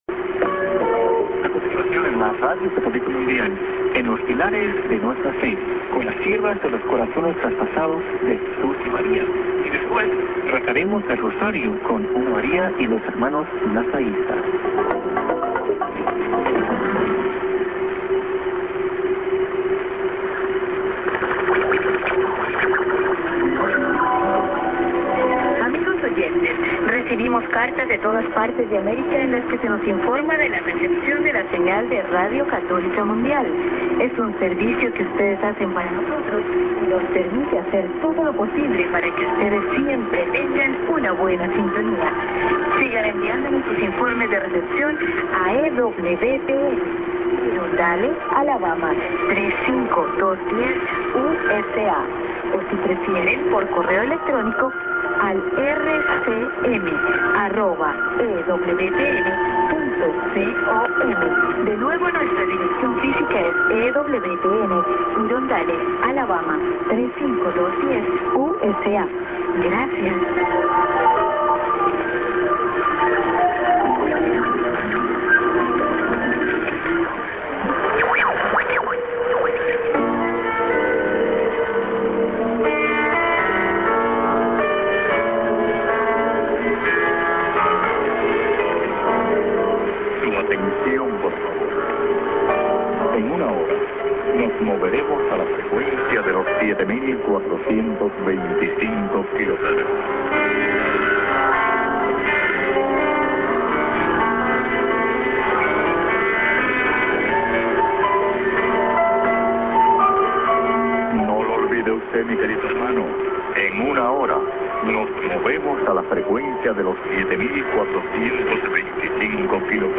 a:　Spanish ID->ID+ADDR(women)->SKJ(man)->music